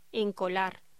Locución: Encolar